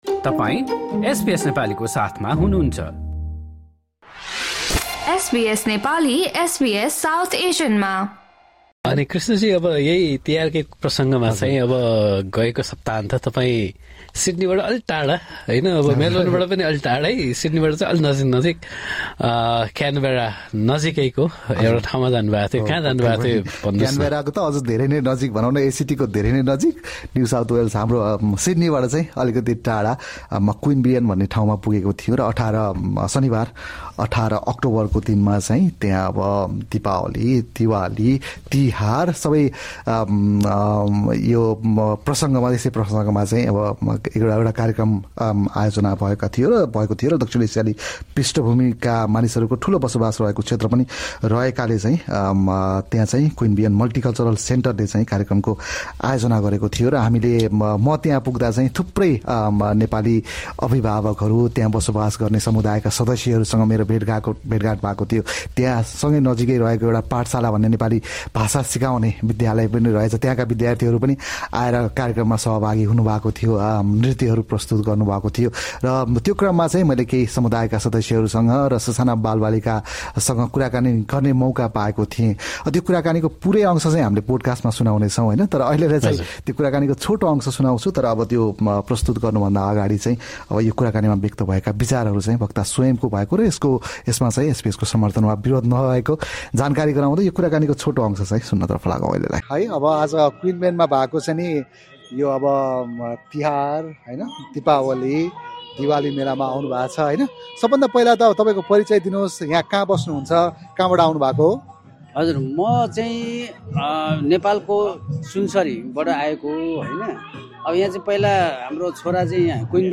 SBS Nepali spoke to pariticipants and visitors at the Diwali Mela Lantern Festival organised by Queanbeyan Multicultural Centre on Saturday, October 18. Nepali community members shared what it means to celebrate festivals away from home, and how it has evolved over time.